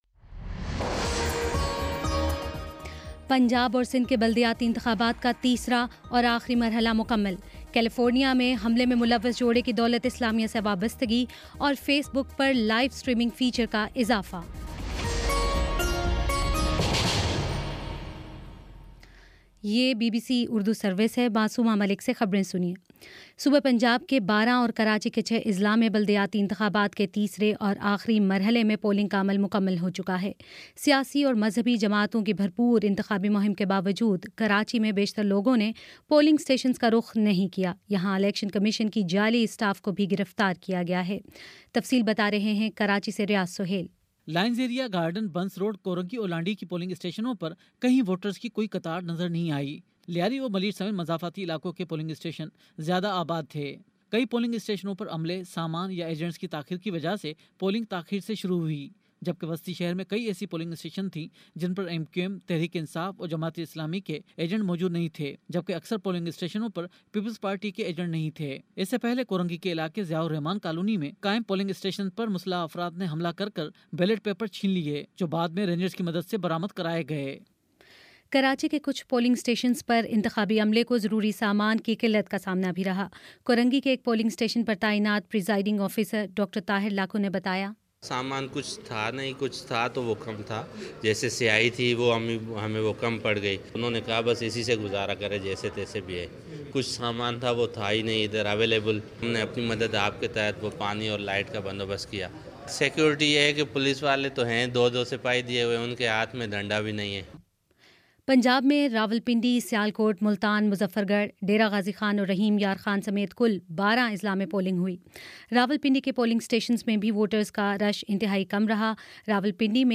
دسمبر 05: شام سات بجے کا نیوز بُلیٹن